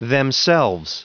Prononciation du mot themselves en anglais (fichier audio)
Prononciation du mot : themselves